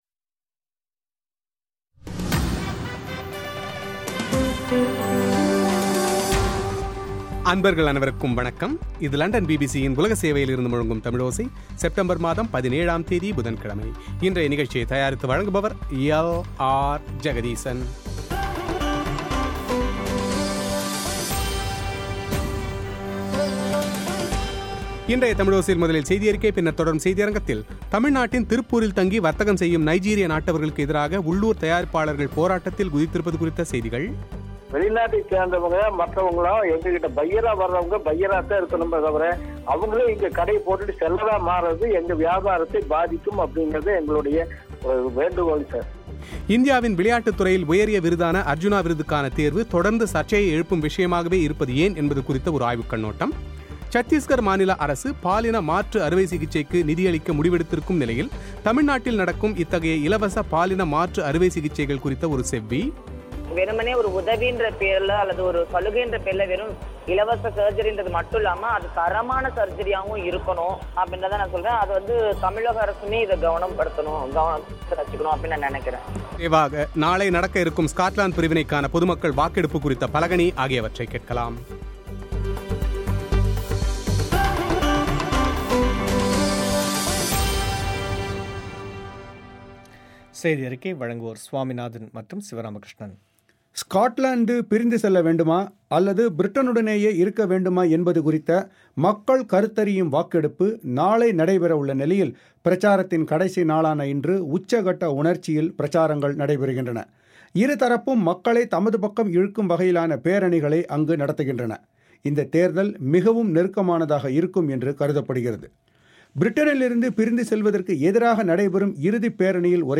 சத்தீஷ்கர் மாநில அரசு பாலின மாற்று சிகிச்சைக்கு நிதியளிக்க முடிவெடுத்திருக்கும் நிலையில் தமிழ்நாட்டில் நடக்கும் இத்தகைய இலவச பாலின மாற்று அறுவை சிகிச்சைகள் குறித்த ஒரு செவ்வி;